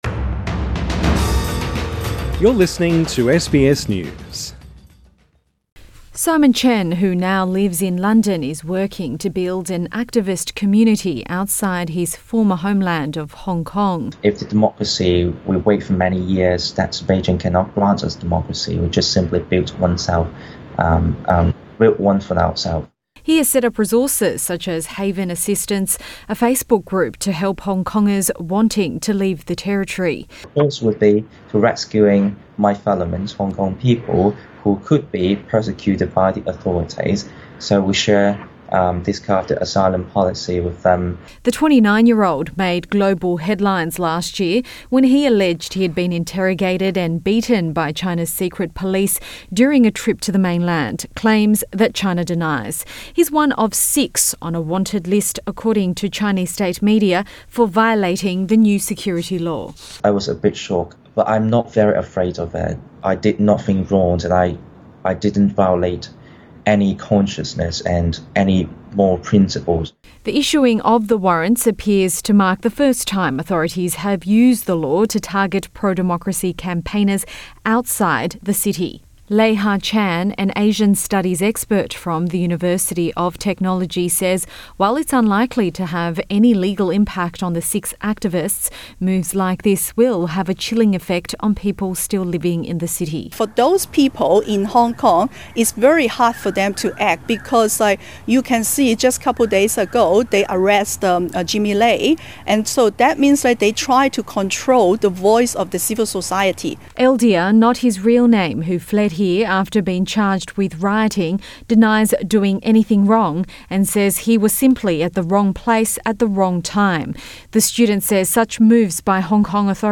speaking to SBS from London via video call